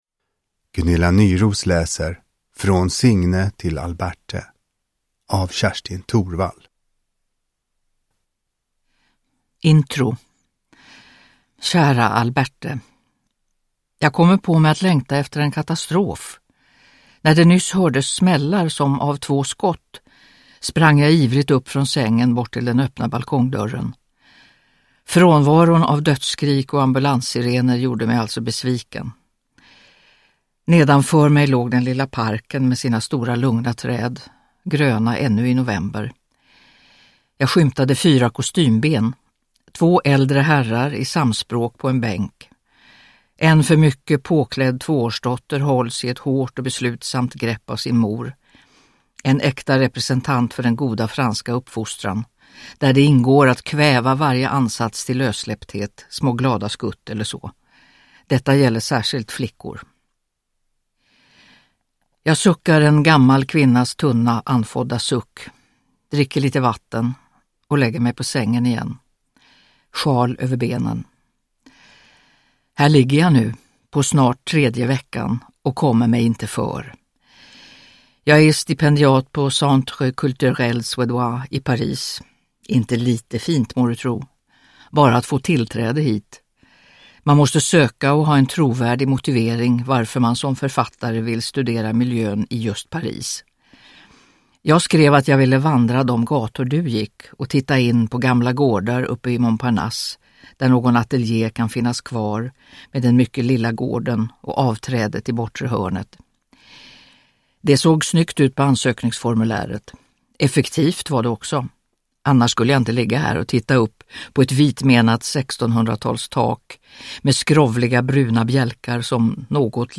Uppläsare: Gunilla Nyroos
Ljudbok